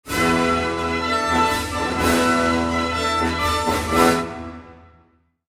На этой странице собраны звуки викторин — от классических сигналов правильного ответа до зажигательных фанфар.